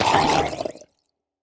Minecraft Version Minecraft Version snapshot Latest Release | Latest Snapshot snapshot / assets / minecraft / sounds / mob / drowned / water / death1.ogg Compare With Compare With Latest Release | Latest Snapshot